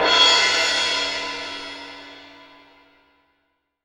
Index of /90_sSampleCDs/AKAI S6000 CD-ROM - Volume 3/Crash_Cymbal2/MALLET_CYMBAL